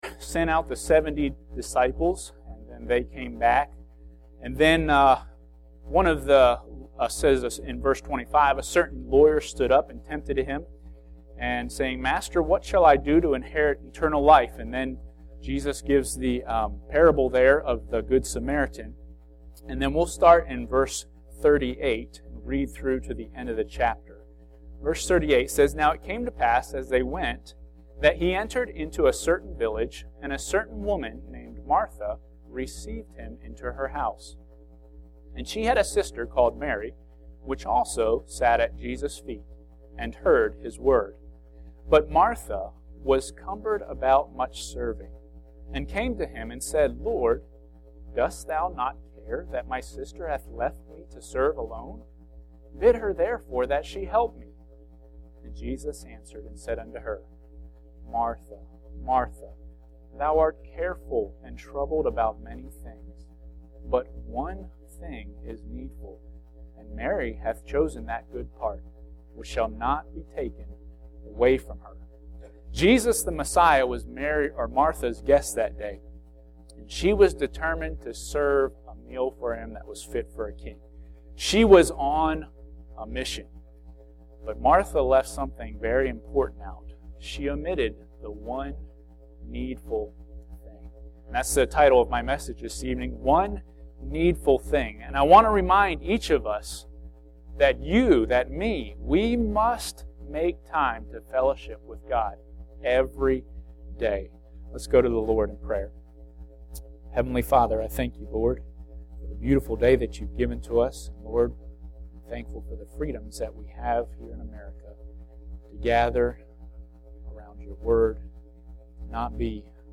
Passage: Luke 10:38-42 Service Type: Midweek Service Bible Text